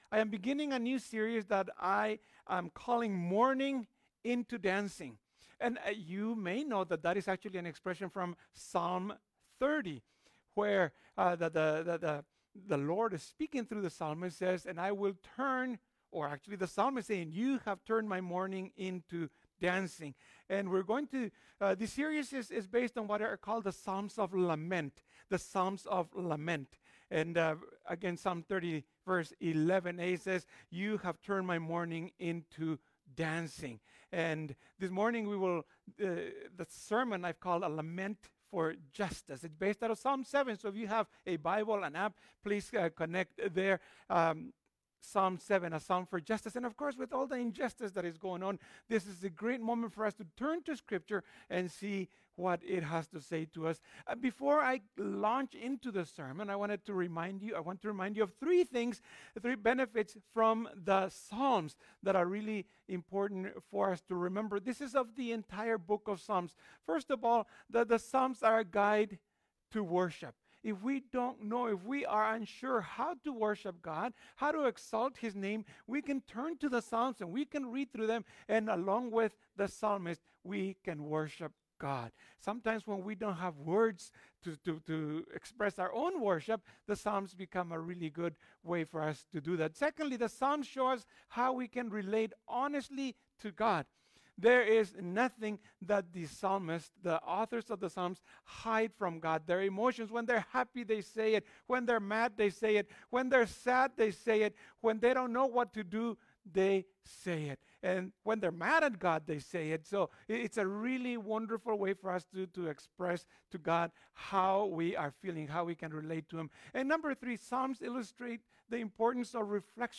Ths is the first sermon in the series, so it provides a brief introduction to the Psalms in general and the Psalms of lament in particular.